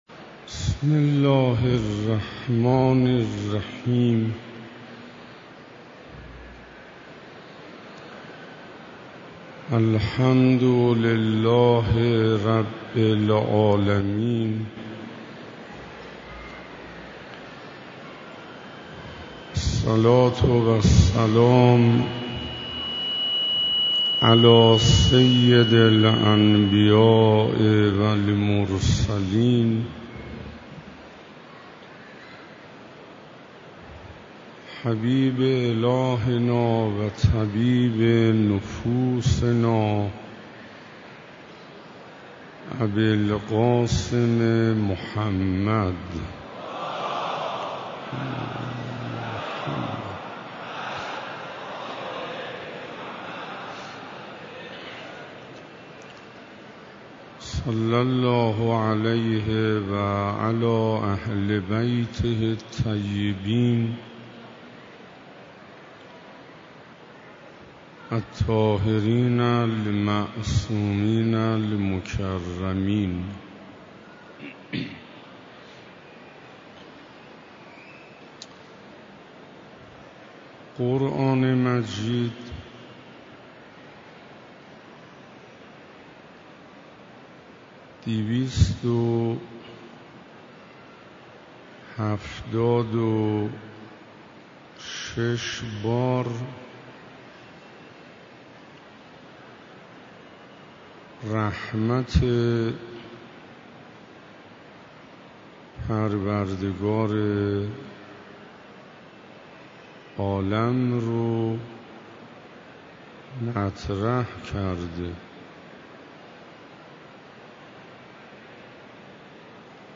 دهه دوم شعبان 97 - جلسه هفتم - حرم مطهر حضرت فاطمه معصومه (س) - عمر